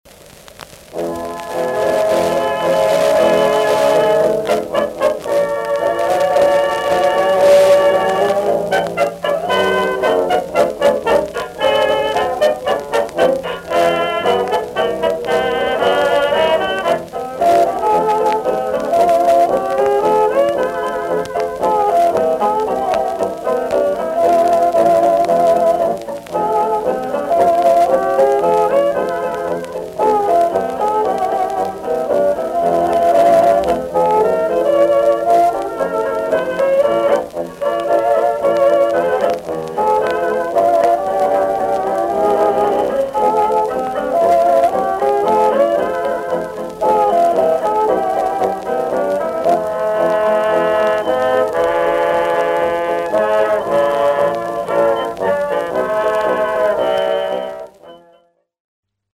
RIAA de-rumble 3.0Mil Elliptical